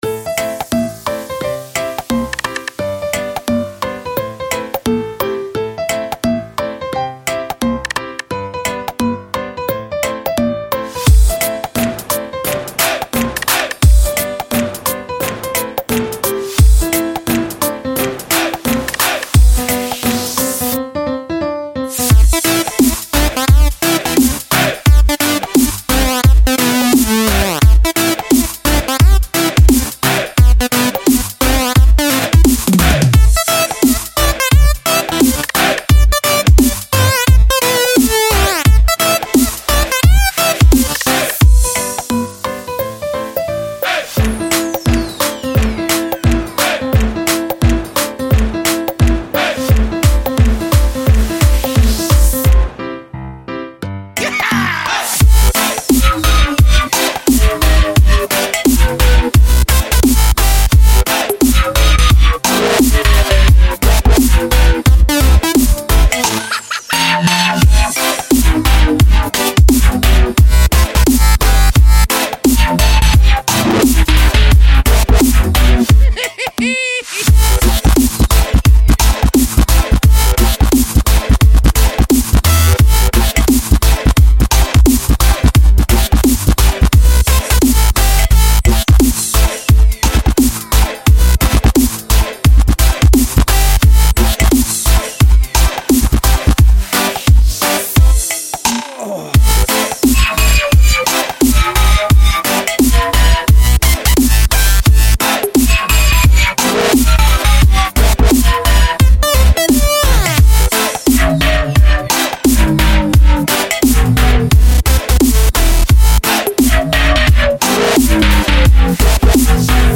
Žánr: Electro/Dance
Genres: Dubstep, Music, Electronic, Dance